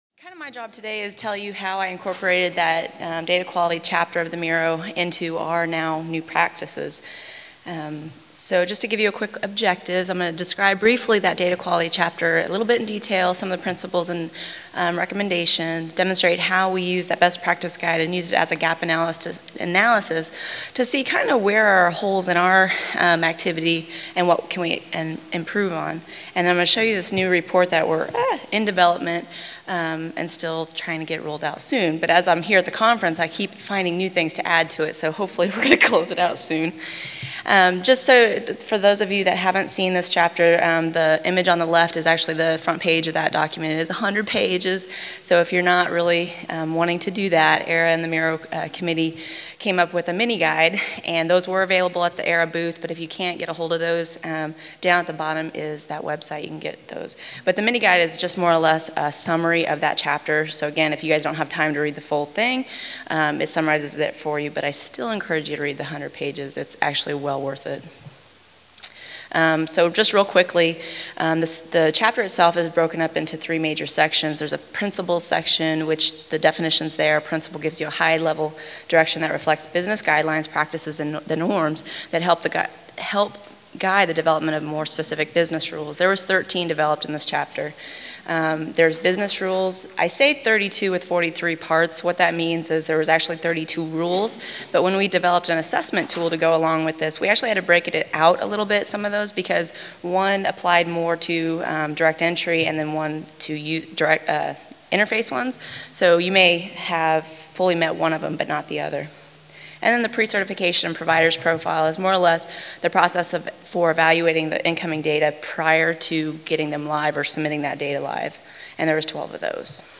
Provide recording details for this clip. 43rd National Immunization Conference (NIC): Modeling of Immunization Registry Operations Workgroup (MIROW) Best Practices